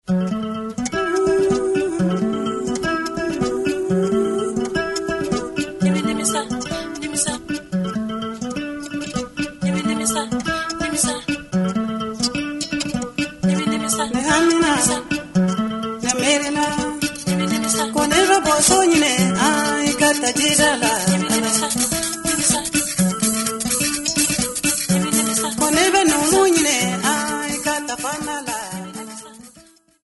kamelen'ngoni
chorus
accoustic and electric guitar
calebasse
Popular music--Africa, West
Folk music
sound recording-musical
Cassette tape